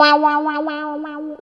64 GUIT 2 -R.wav